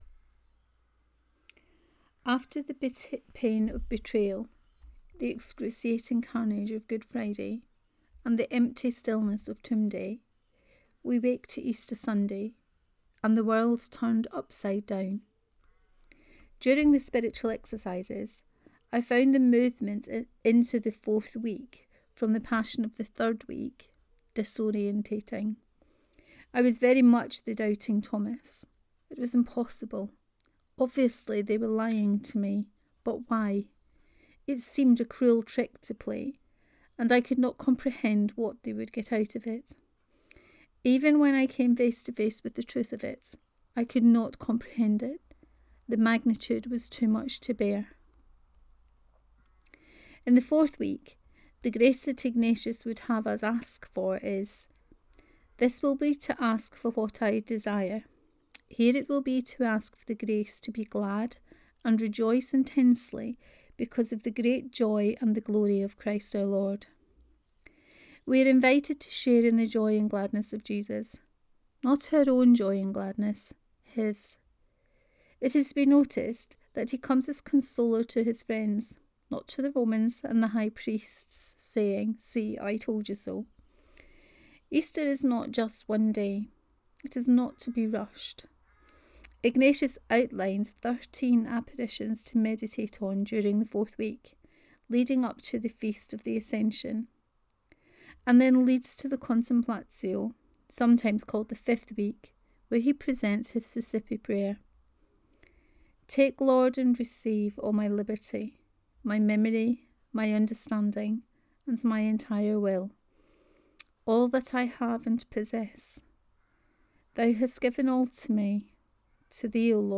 The Generosity of God 2: Reading of this post.